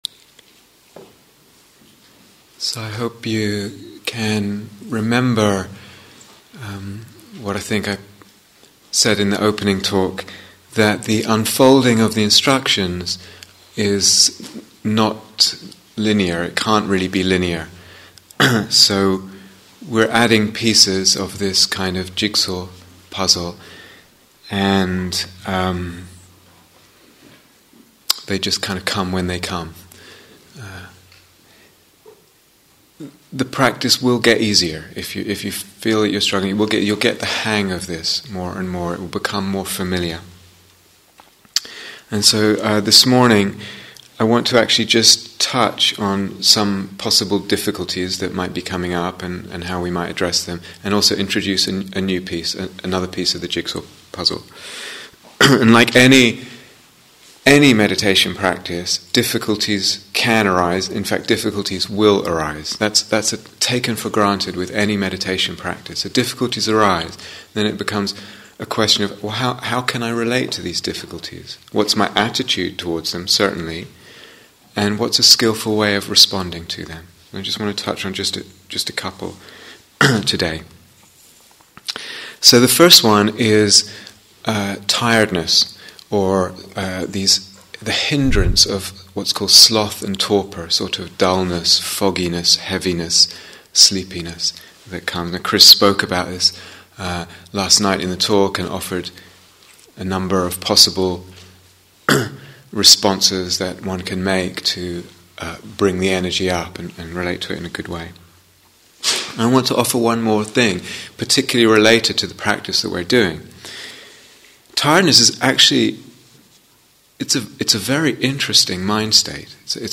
Working with the Emotional Body (Instructions and Guided Meditation: Day Two)